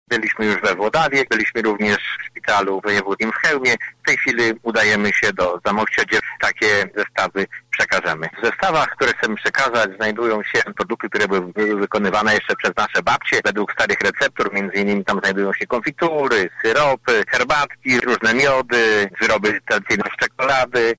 • mówi członek Zarządu Województwa Lubelskiego Zdzisław Szwed.